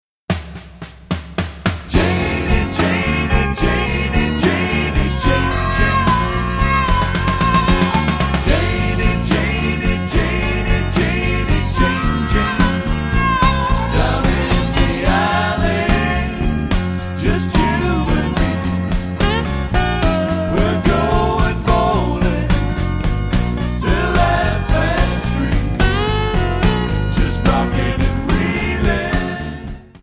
Backing track files: 1960s (842)